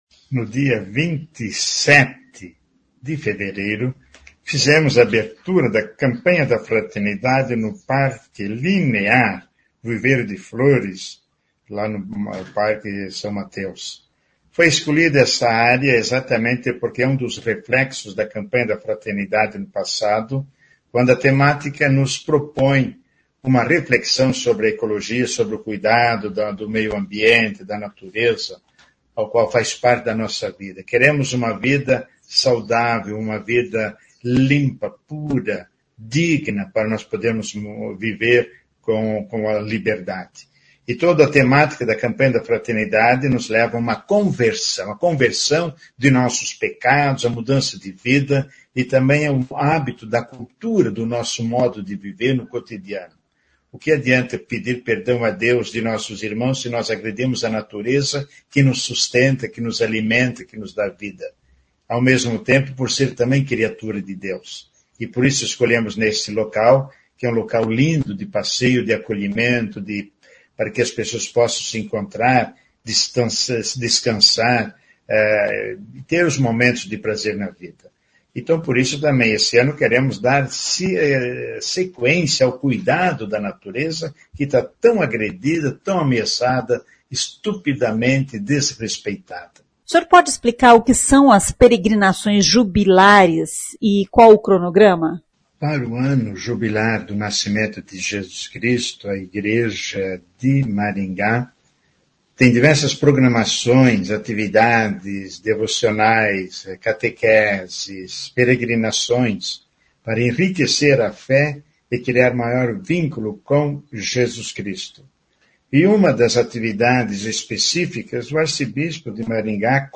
O arcebispo de Maringá dom Frei Severino Clasen explica o que são as peregrinações jubilares que comemoram os 2025 anos do nascimento de Jesus Cristo.
Frei Severino também explica a escolha do local para o lançamento da Campanha da Fraternidade em Maringá.